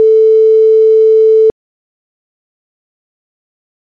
ringtone.mp3